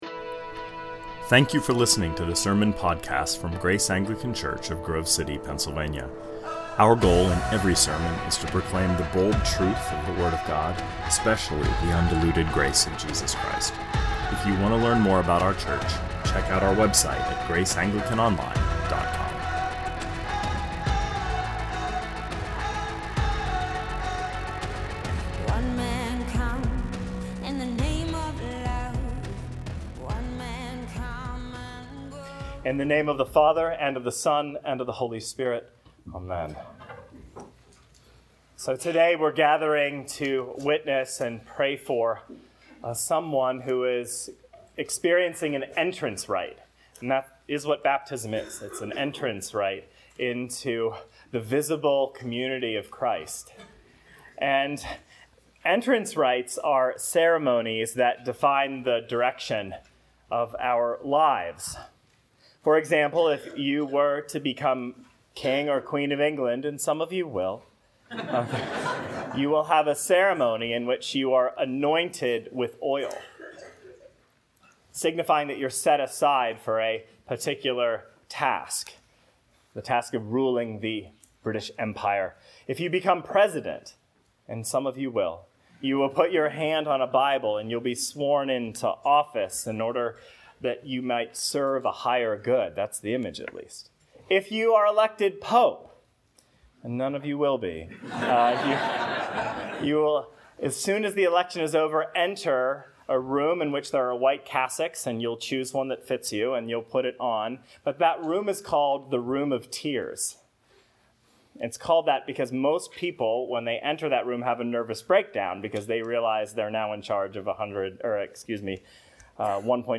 A homily